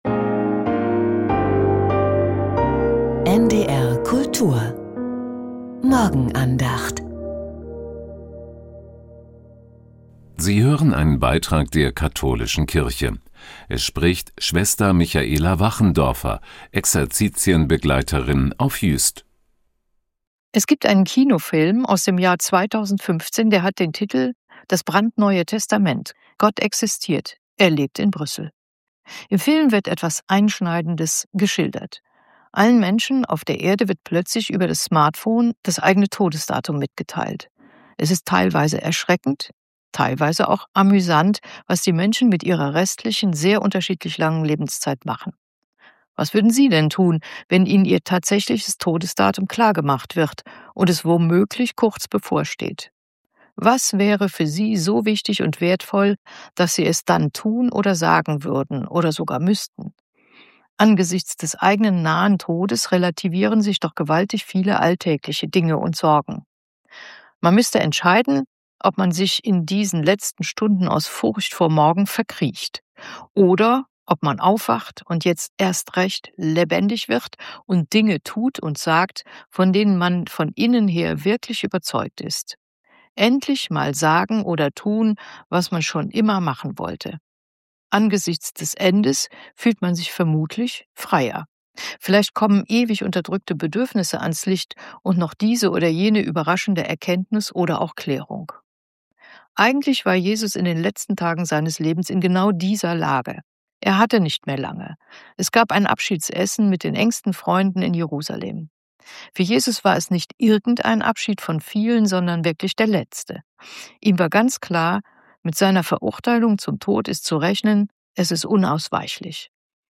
Die Morgenandacht bei NDR Kultur